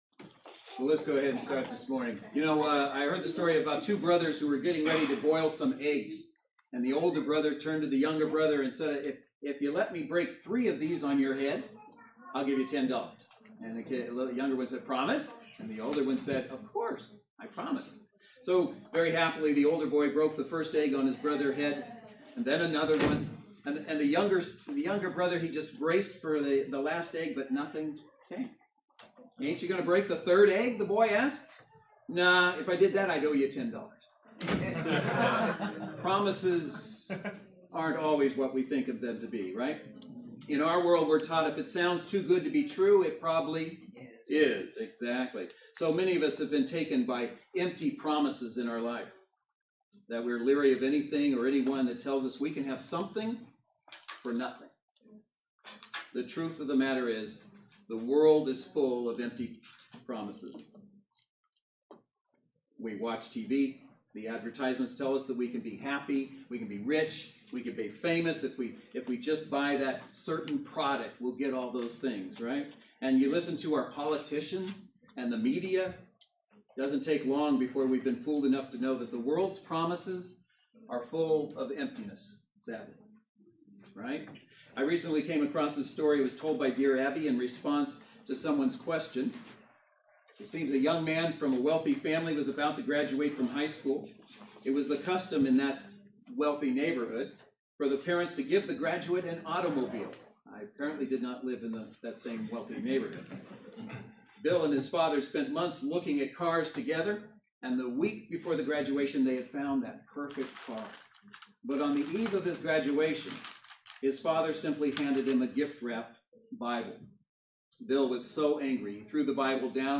Easter-Service-at-Maranatha.mp3